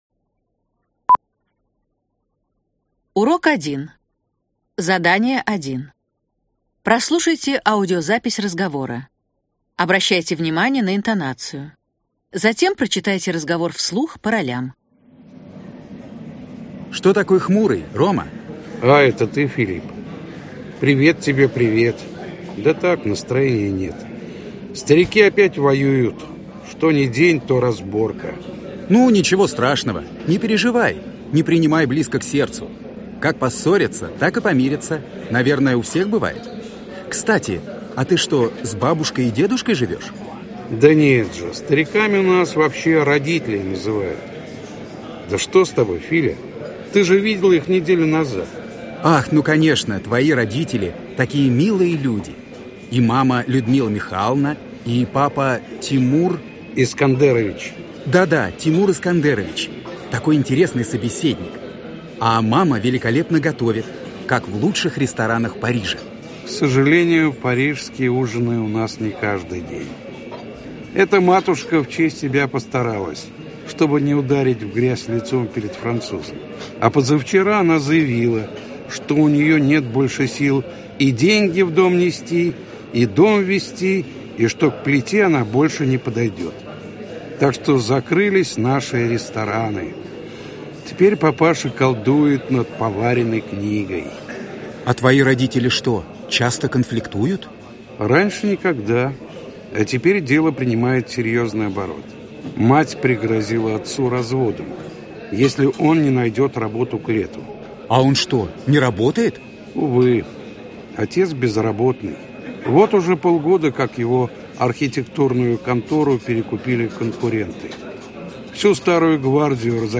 Аудиокнига Окно в Россию. Учебное пособие по русскому языку как иностранному для продвинутого этапа. Часть 1 | Библиотека аудиокниг